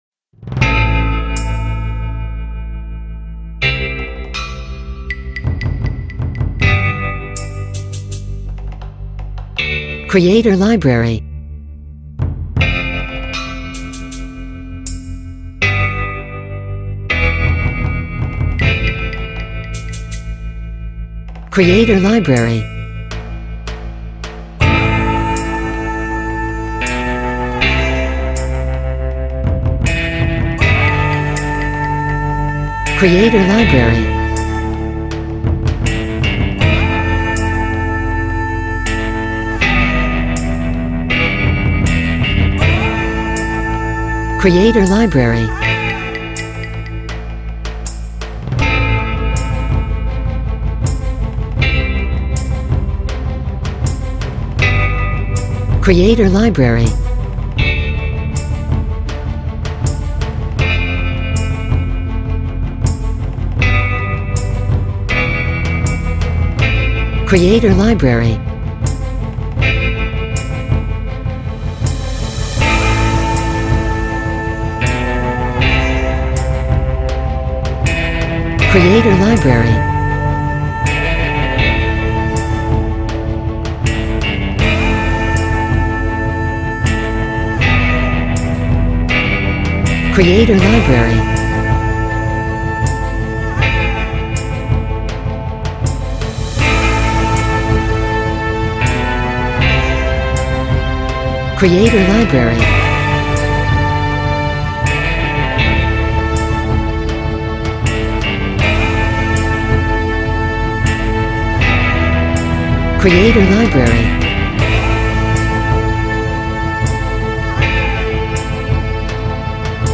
Epic-Western